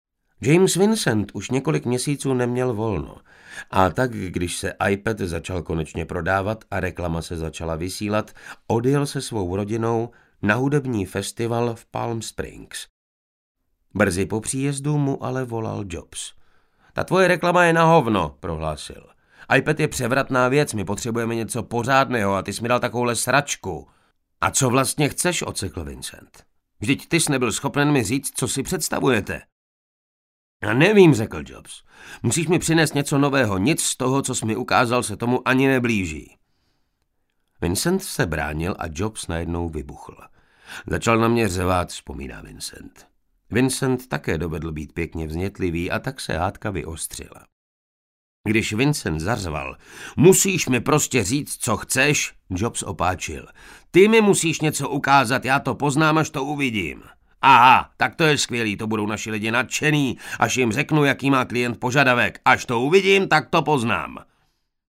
Audiokniha vznikla a byla produkována ve studiích ADK-Prague.